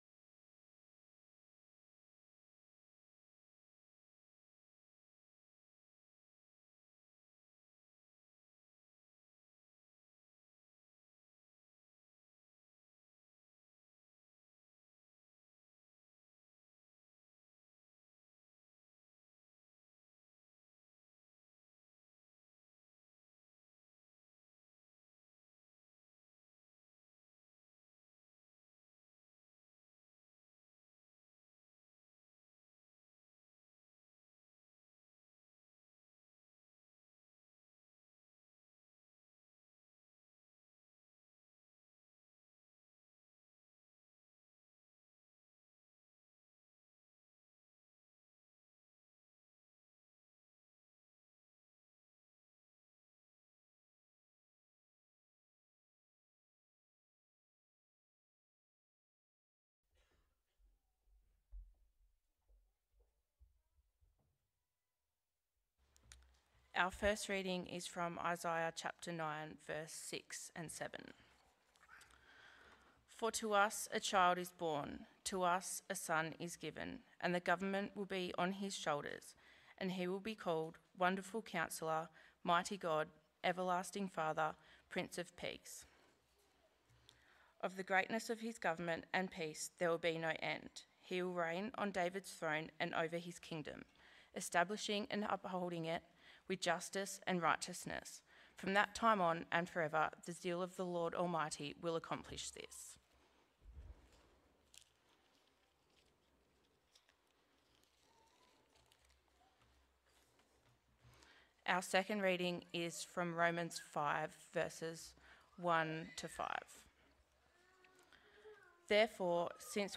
Current Sermon He Will Be Called...Prince of Peace He Will Be Called...